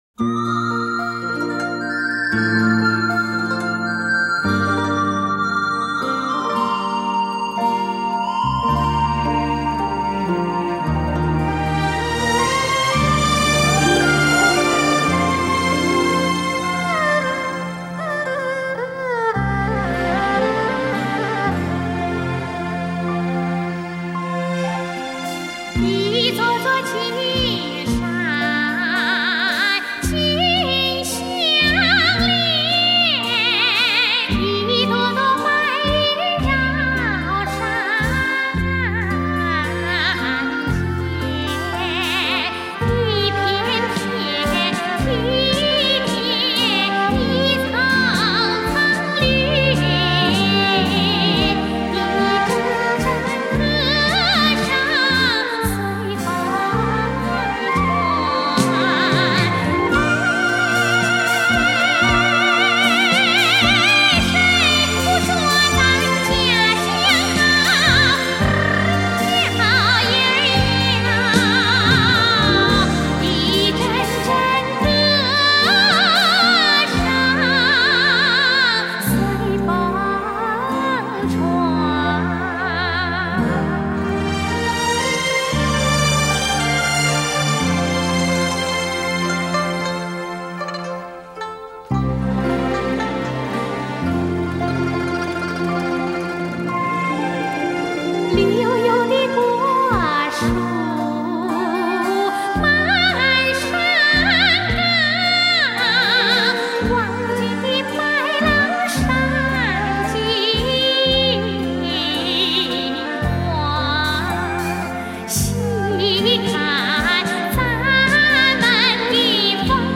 音乐类型：民乐
她的演唱有一种天赋的美感——坦诚、亲切，洋溢着青春气息。